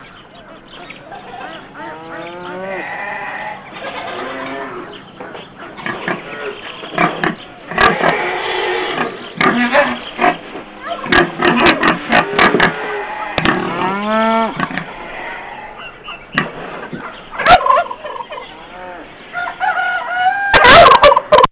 Barnyard
BARNYARD.wav